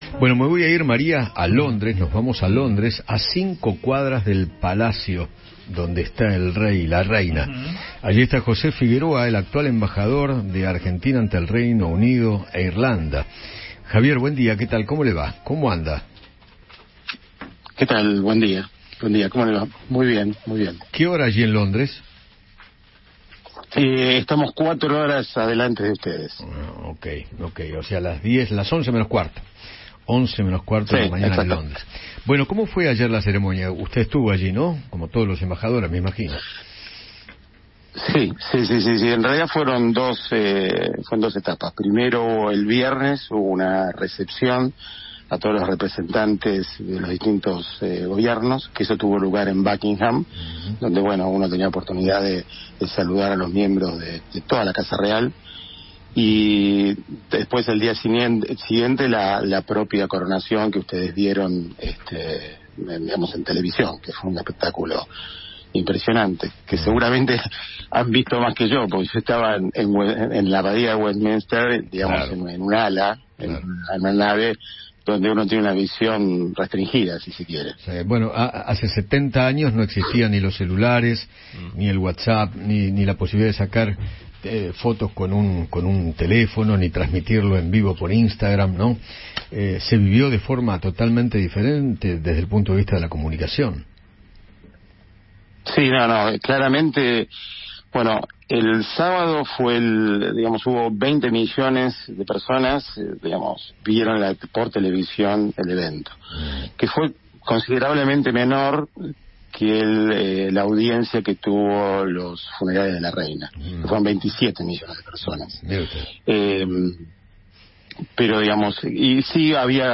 Javier Figueroa, embajador argentino en el Reino Unido, dialogó con Eduardo Feinmann sobre la ceremonia de coronación de Carlos III.